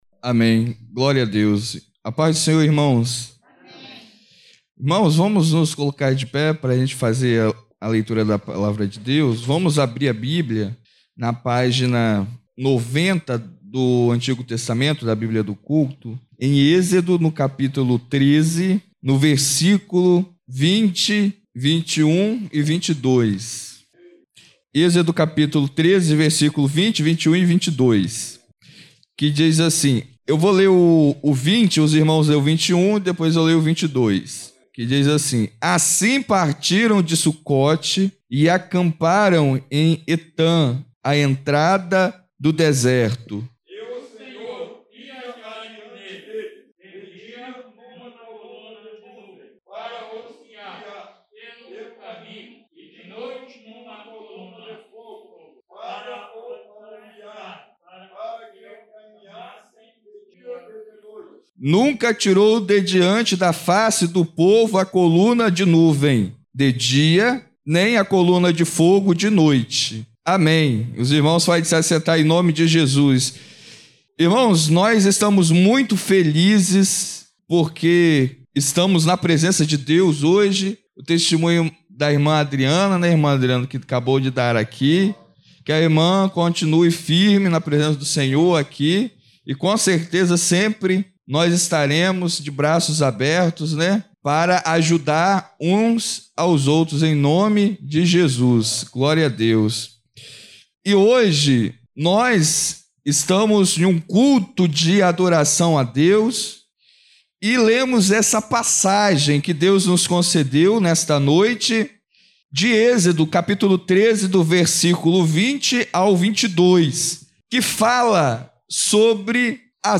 Culto de Adoração